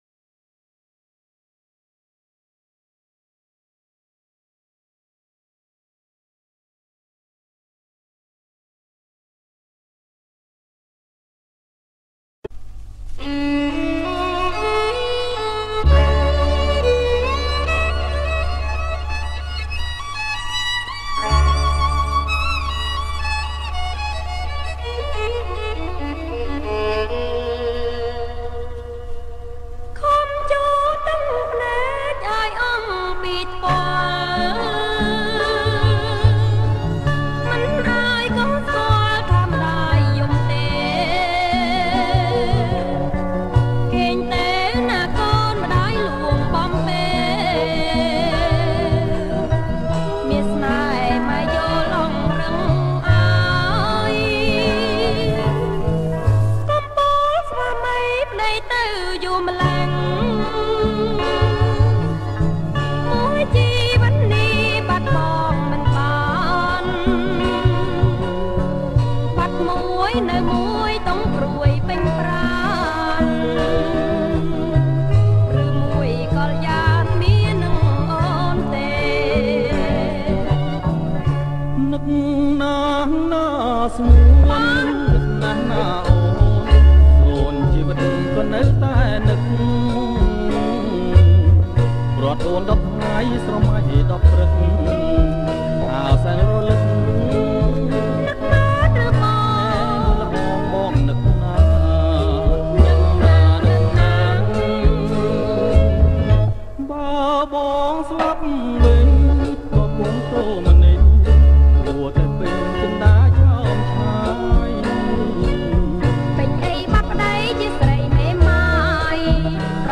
• ប្រគំជាចង្វាក់ Slow Twist